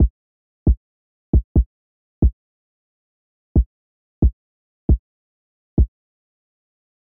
描述：135 BPM。享受这些简单的鼓声循环，为你的节奏服务。
Tag: 135 bpm Trap Loops Drum Loops 1.20 MB wav Key : Unknown